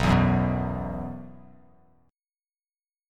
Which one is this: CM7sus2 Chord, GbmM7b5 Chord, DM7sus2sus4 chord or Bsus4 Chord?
Bsus4 Chord